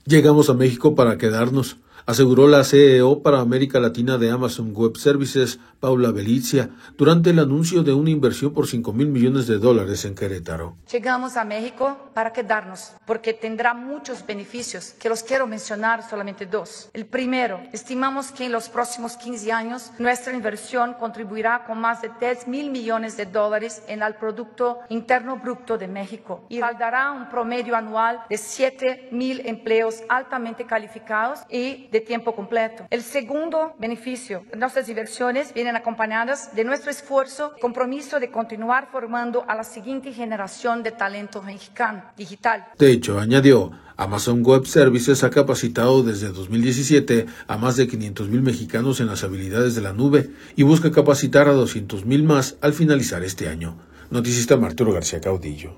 durante el anuncio de una inversión por cinco mil millones de dólares en Querétaro.